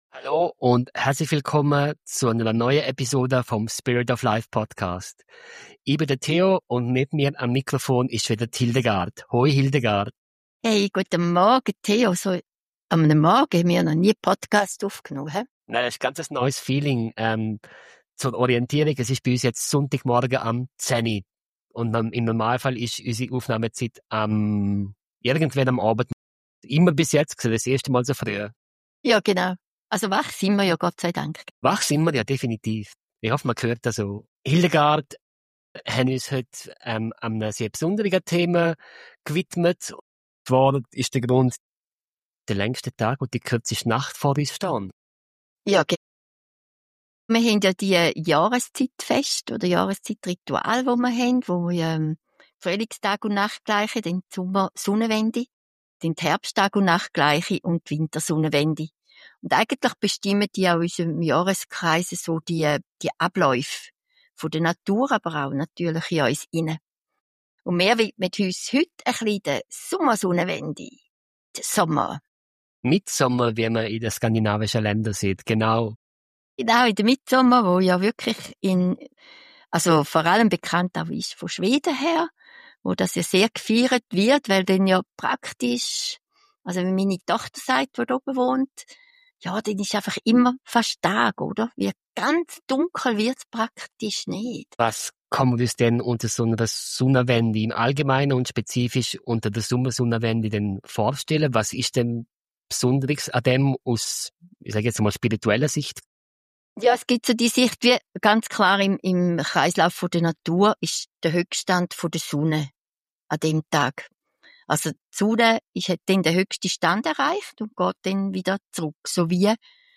Ein Gespräch über die Magie des Augenblicks, über bewusstes Erleben – und über den Mut zur Freude in einer oft schwerfälligen Welt.